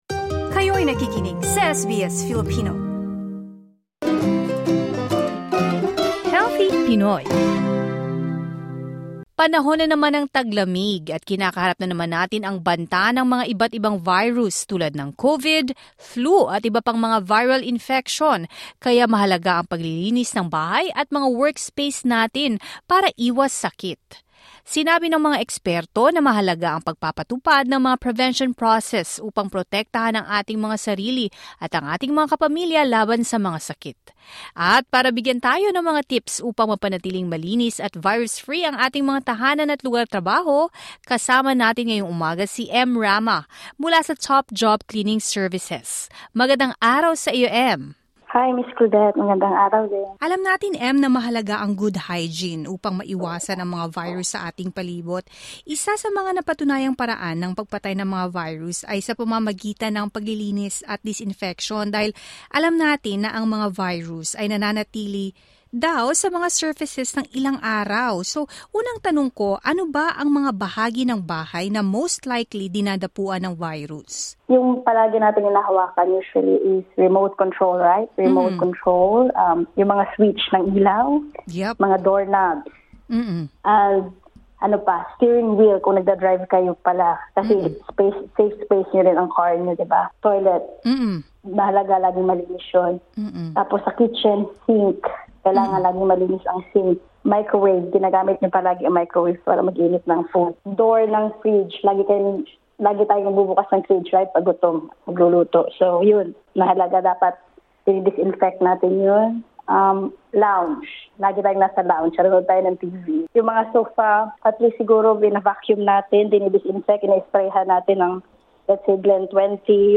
PAKINGGAN ANG PODCAST Bigyan ng prayoridad ang kalinisan ngayong taglamig upang labanan ang pagkalat ng virus SBS Filipino 08:34 Filipino Disclaimer: Ang mga impormasyon sa panayam na ito ay gabay lamang.